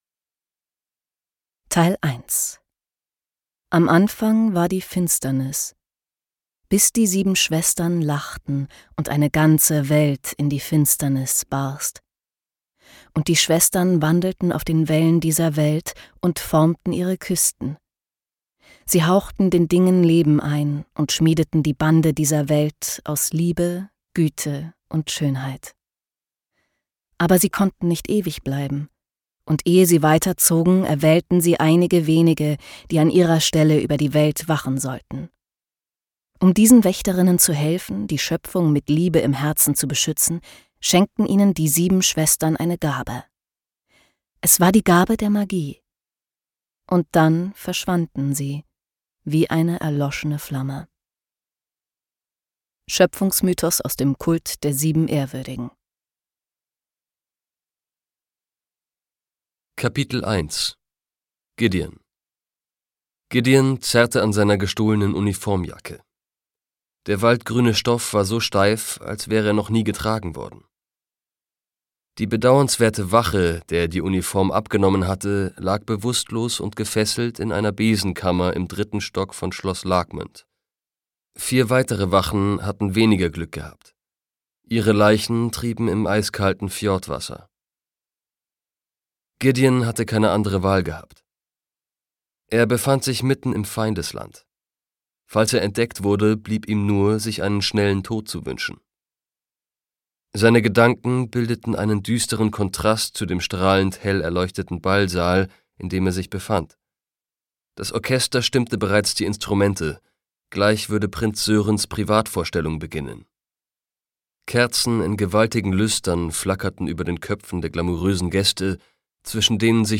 Gekürzt Autorisierte, d.h. von Autor:innen und / oder Verlagen freigegebene, bearbeitete Fassung.
Hörbuchcover von Rebel Witch. Der rote Nachtfalter, Band 2